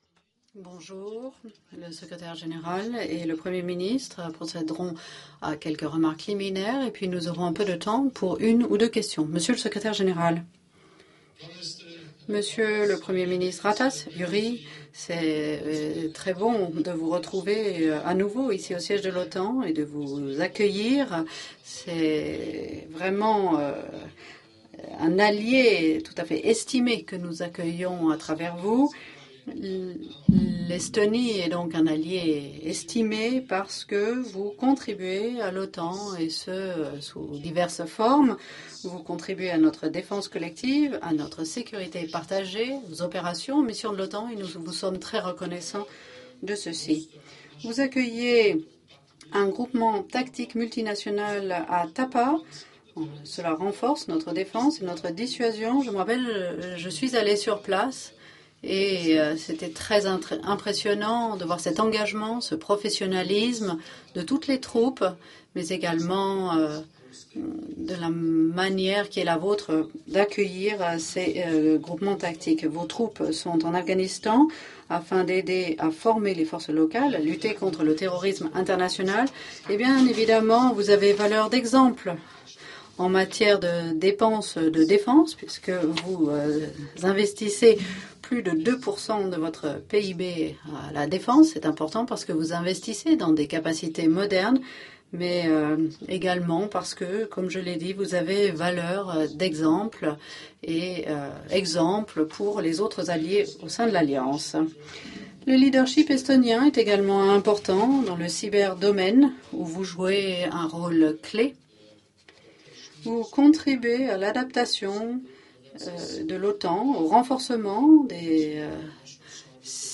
Remarks by NATO Secretary General Jens Stoltenberg at the joint press point with the Prime Minister of Estonia, Jüri Ratas
(As delivered)